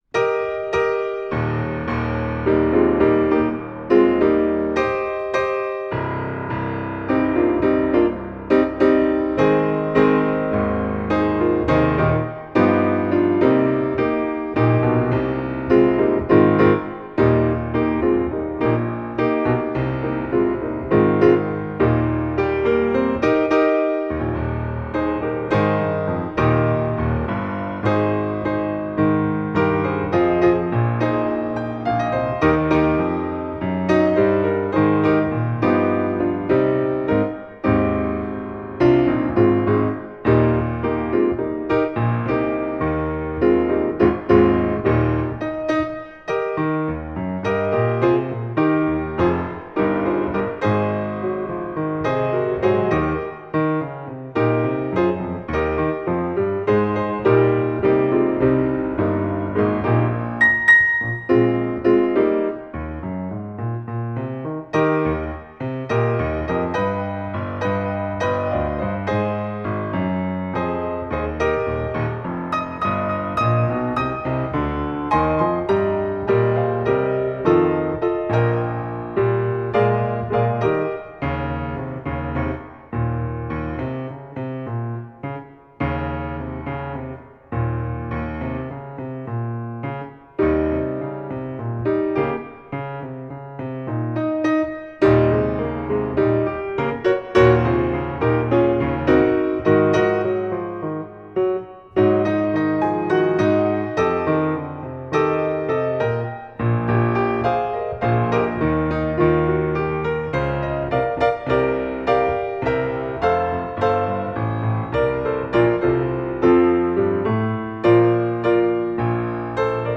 Voicing: Piano Solo Collection